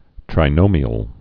(trī-nōmē-əl)